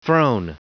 Prononciation du mot throne en anglais (fichier audio)